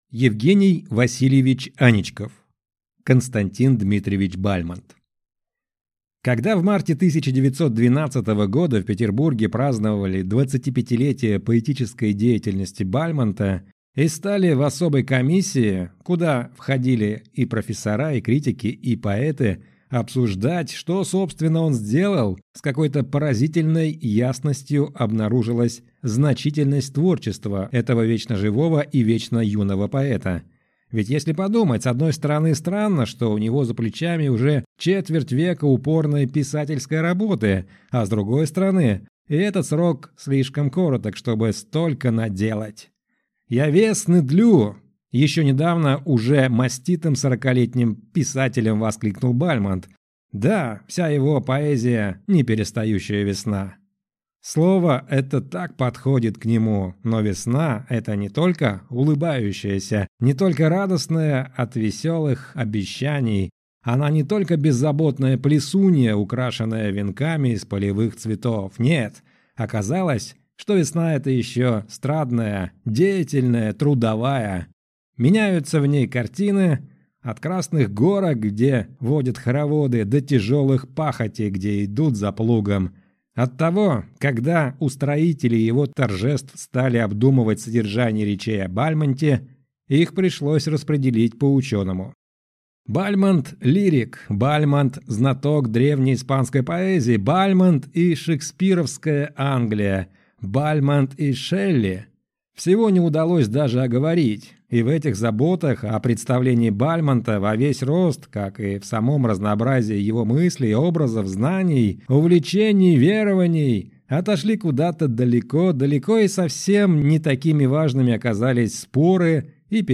Аудиокнига К. Д. Бальмонт | Библиотека аудиокниг